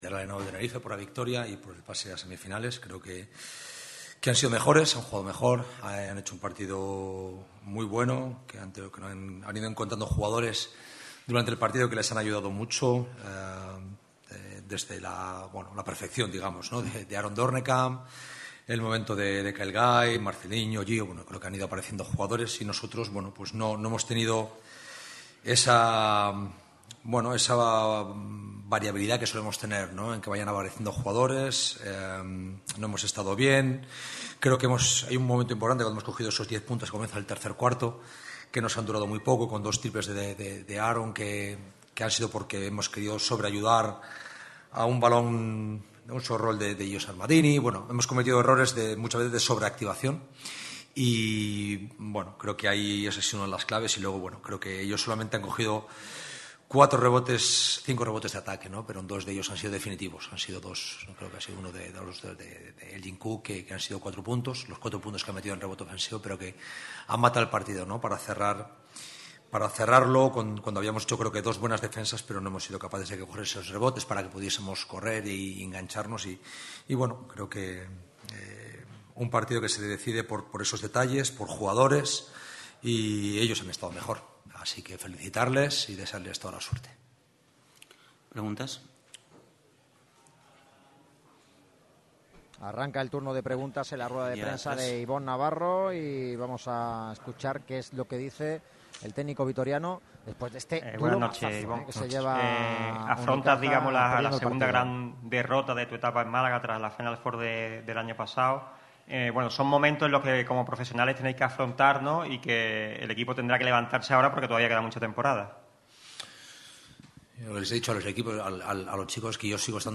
El perfecto partido de Doornekamp y el momento decisivo de Kyle Guy hicieron que los tinerfeños se vean las caras este sábado ante el FC Barcelona en las semifinales del torneo del K.O. De este modo, el técnico vitoriano de Unicaja dio la cara tras la derrota en rueda de prensa y dejó la siguiente frase tras la derrota: «ojalá tener fracasos como este siempre«.